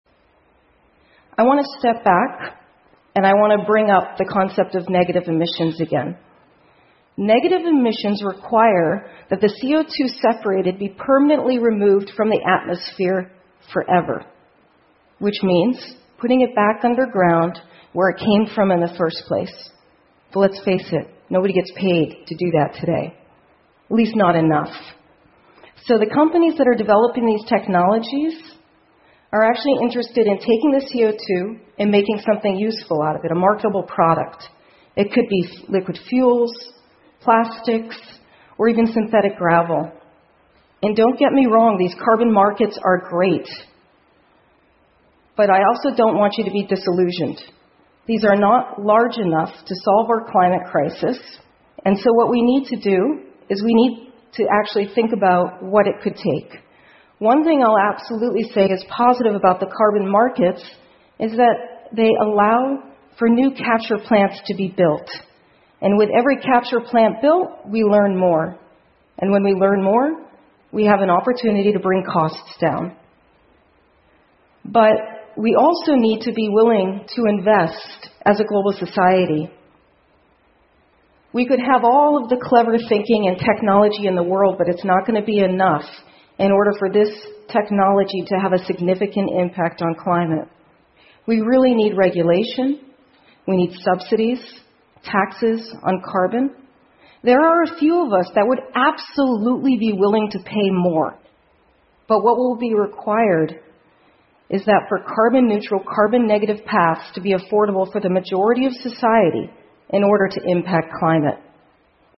TED演讲:从大气中移除二氧化碳的新方法() 听力文件下载—在线英语听力室